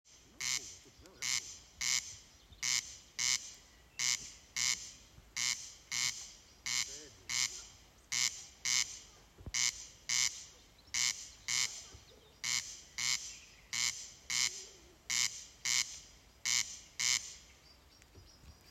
Corn Crake, Crex crex
Administratīvā teritorijaSalacgrīvas novads
StatusSinging male in breeding season